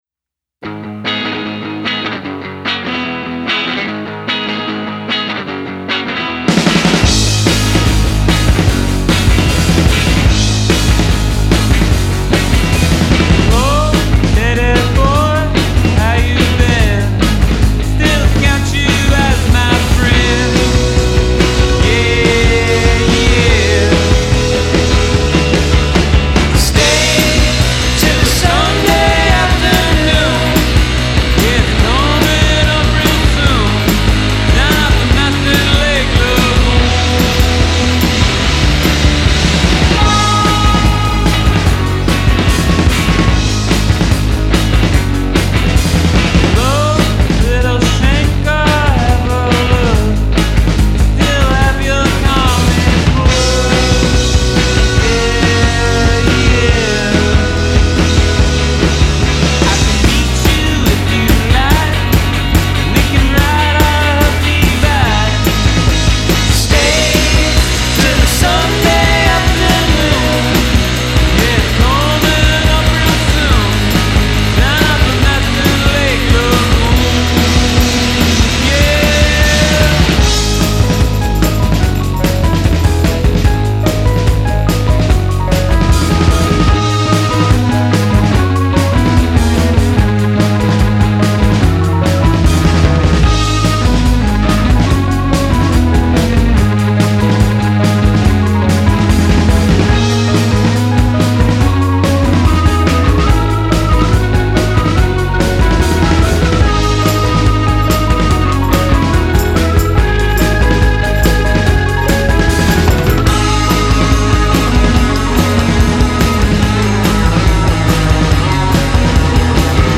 melodic, well-crafted rock’n’roll